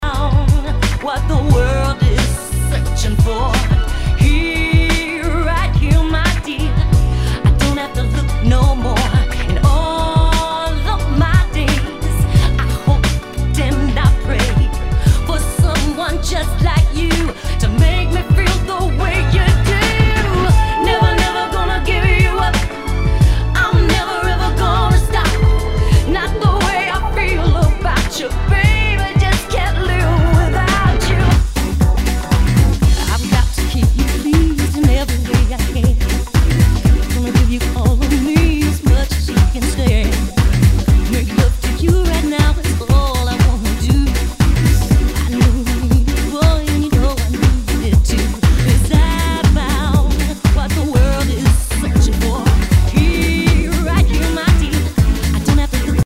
HOUSE/TECHNO/ELECTRO
ナイス！ヴォーカル・ハウス！
[VG ] 平均的中古盤。スレ、キズ少々あり（ストレスに感じない程度のノイズが入ることも有り）